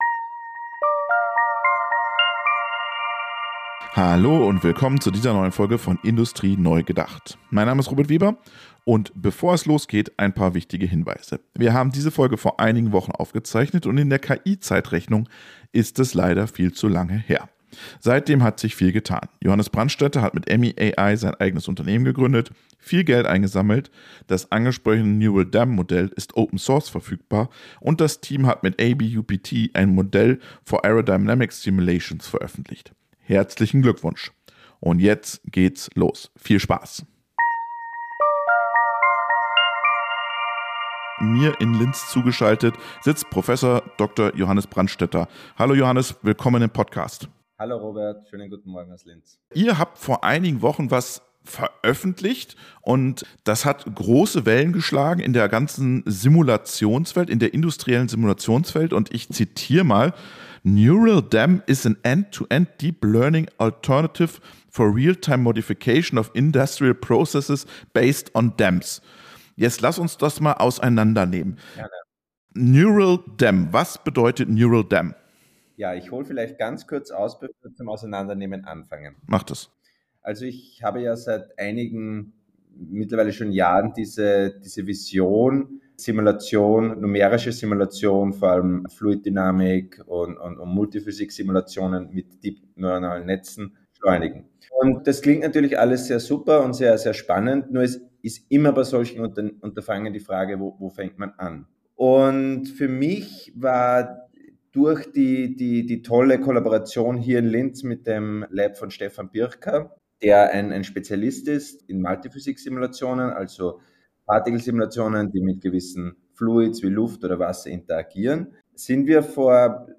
Ein Gespräch über Wissenschaft, Visionen und das Potenzial, ganze Branchen neu zu denken.